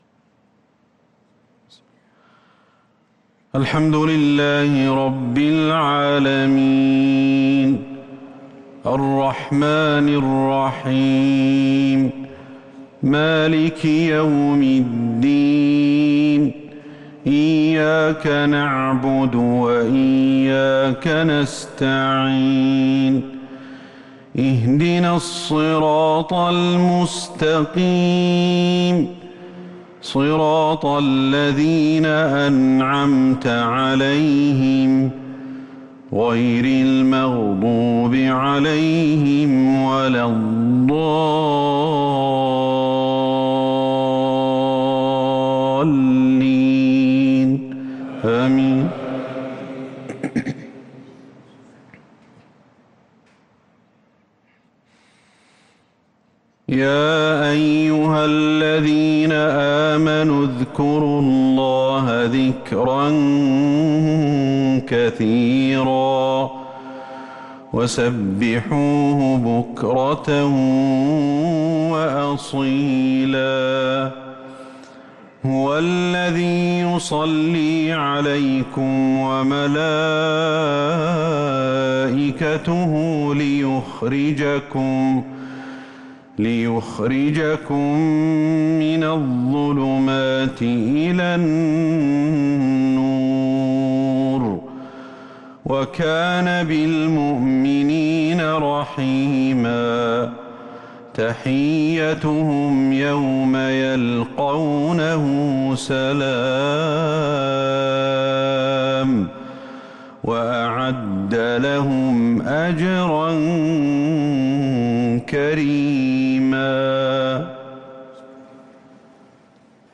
صلاة العشاء للقارئ أحمد الحذيفي 13 رمضان 1443 هـ